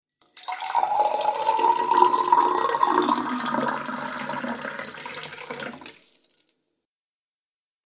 دانلود آهنگ آب 48 از افکت صوتی طبیعت و محیط
دانلود صدای آب 48 از ساعد نیوز با لینک مستقیم و کیفیت بالا
جلوه های صوتی